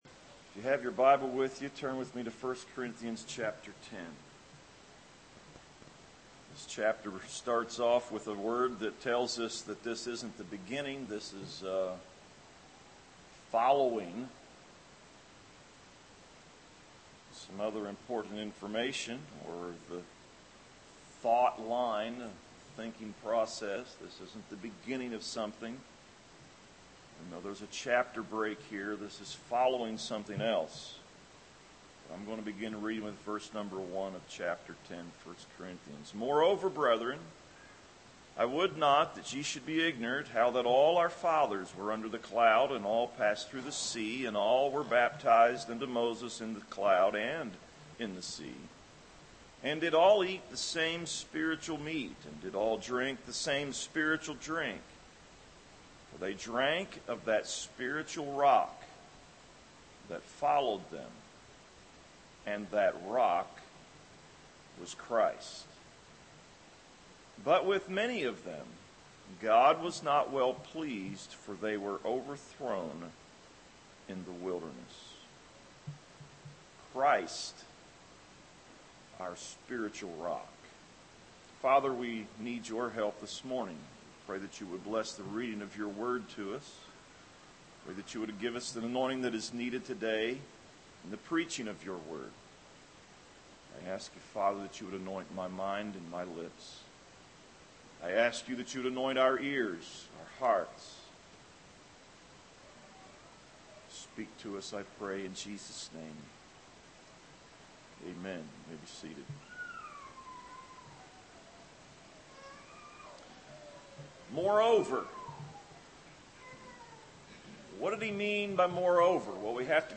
Save Audio A message